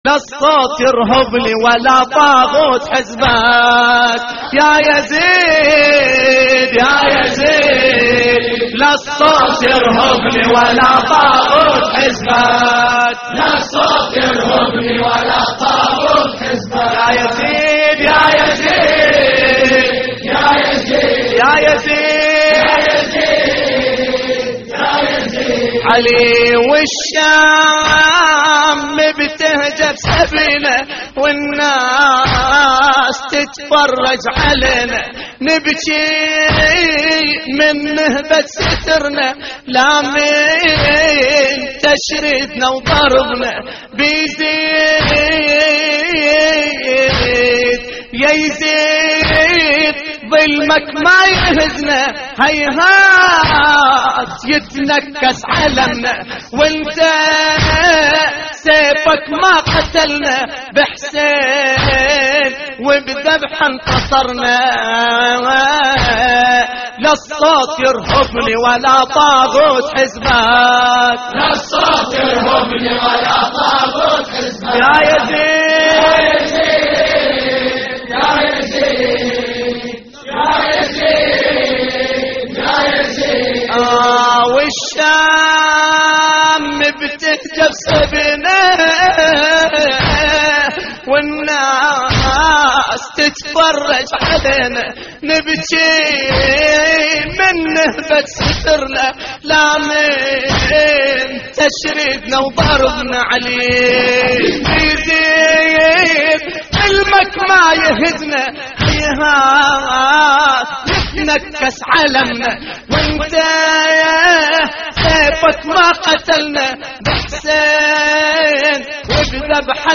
اللطميات الحسينية
استديو